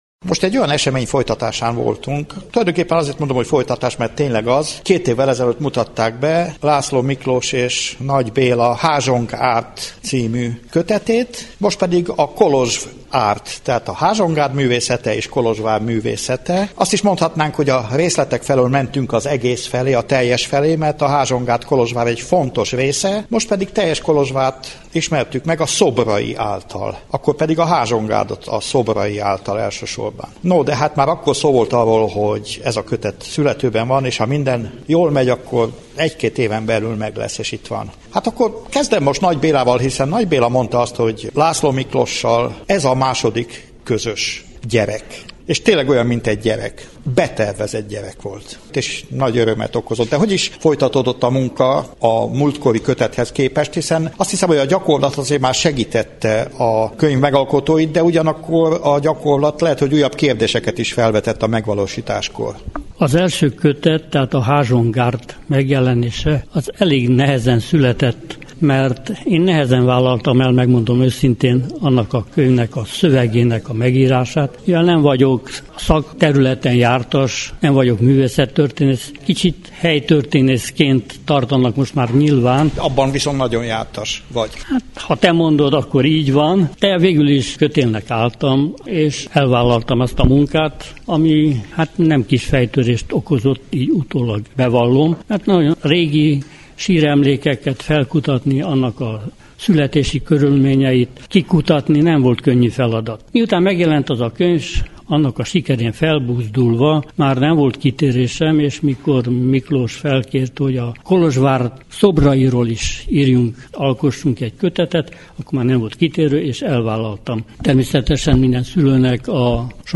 A Bővebben műsorában ezúttal a közelmúltban megjelent, a kolozsvári- erdélyi és az egyetemes magyar történelem és kultúrtörténet fontos témáit feldolgozó könyveket ismerhetnek meg, egyenesen az Adventi Könyvvásárról.